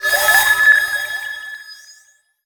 magic_general_item_collect_01.wav